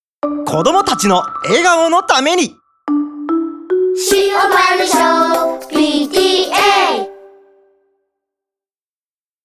生CMと合わせて今回より、保護者のボランティアの方が作成してくださったサウンドロゴが登場しました！
塩原小PTAサウンドロゴ_Long-01.mp3